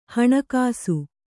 ♪ haṇa kāsu